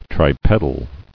[tri·ped·al]